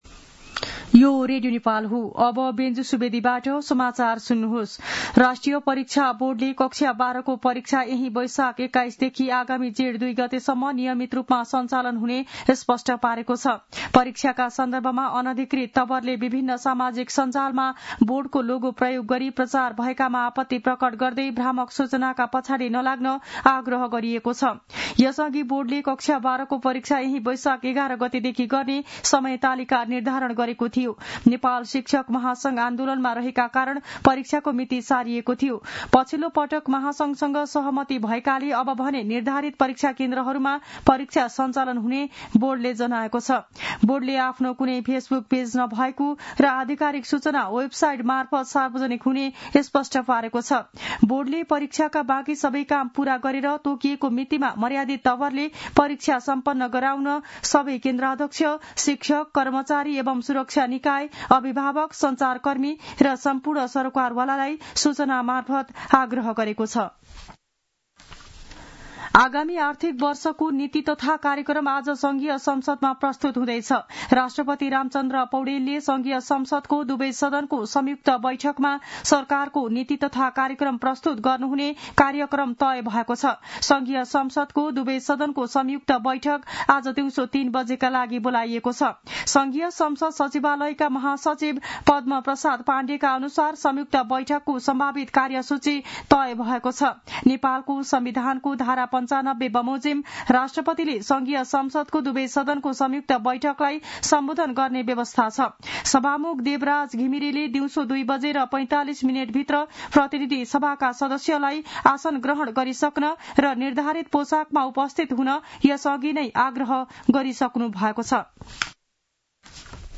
मध्यान्ह १२ बजेको नेपाली समाचार : १९ वैशाख , २०८२
12-am-news-.mp3